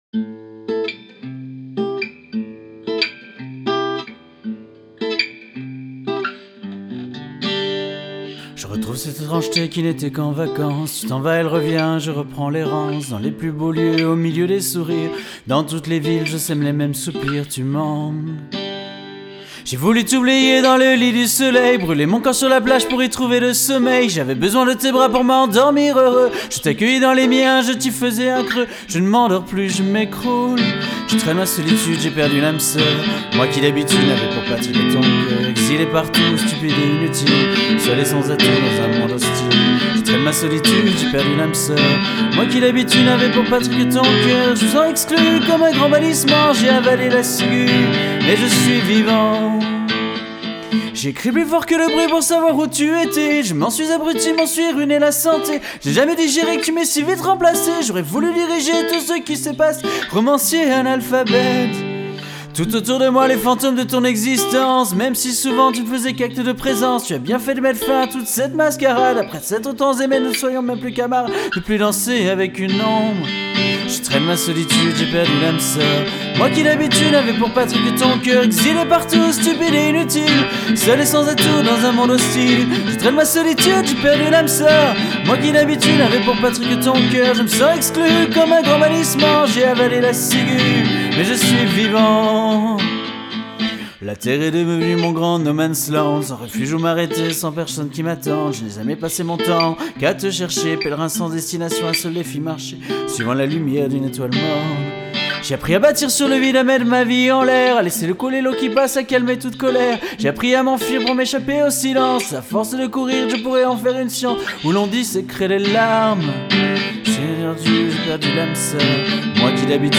• Voix
• Guitare